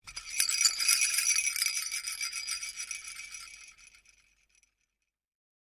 Коллекция включает разные варианты: от мягких перезвонов до более ритмичных звуков.
Звук детской пластмассовой погремушки